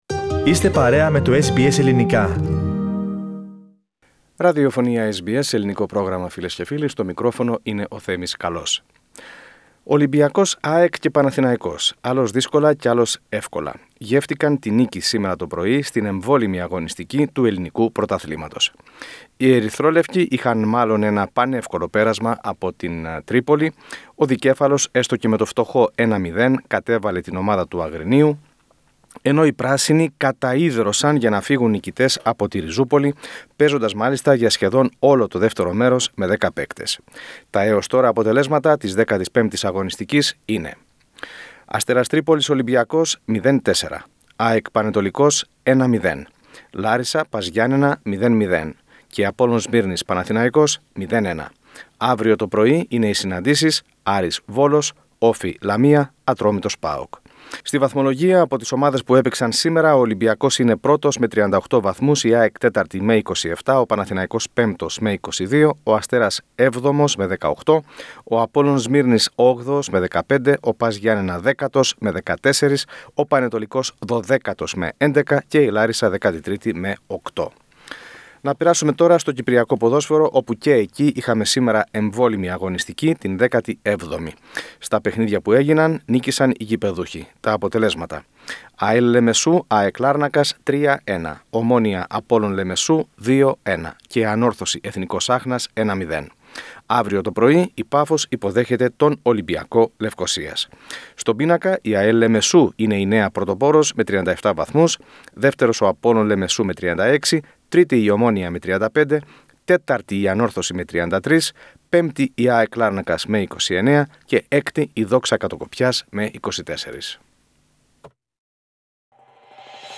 Αθλητικό δελτίο: Νίκες για Ολυμπιακό, ΑΕΚ, ΠΑΟ